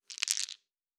Fantasy Interface Sounds
Dice Shake 7.wav